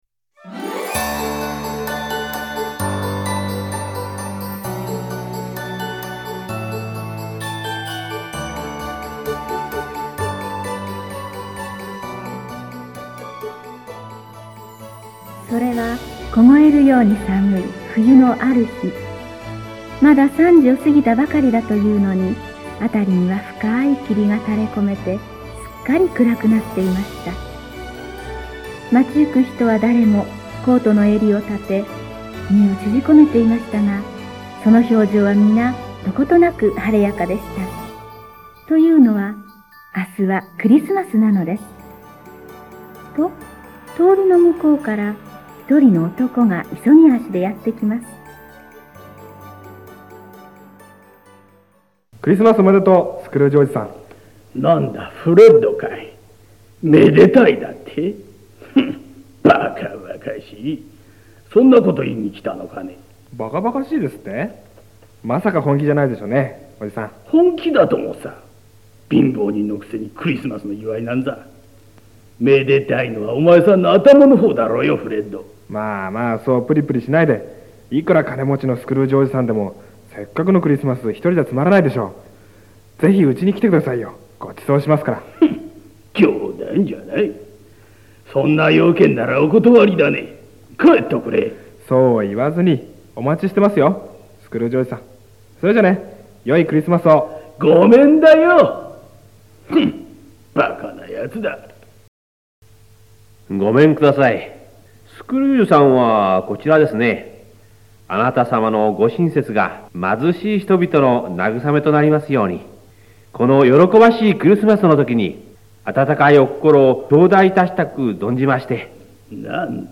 ディケンズ原作の古典文学のドラマ化。強欲老人スクルージは、クリスマスになっても、相も変わらずのごうつくぶりだったが、うたた寝をして幽霊に連れていかれ、次々に見せられたものは…？